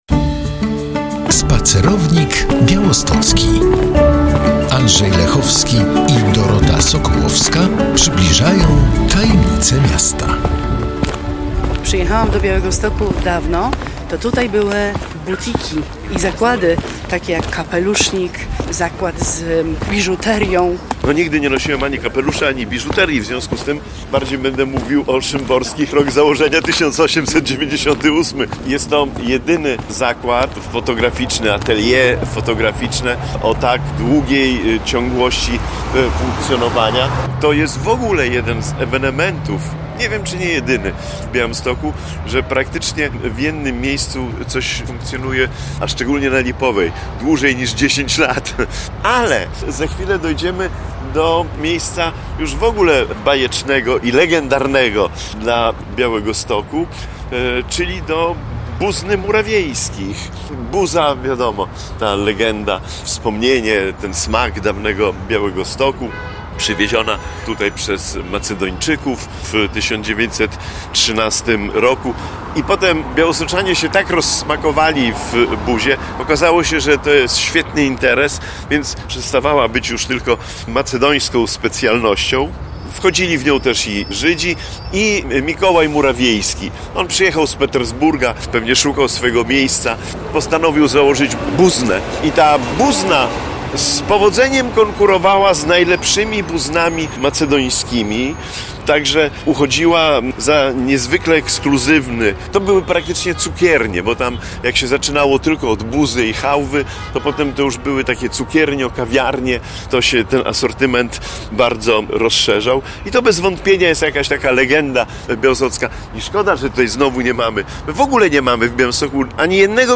O tym, spacerując, rozprawiają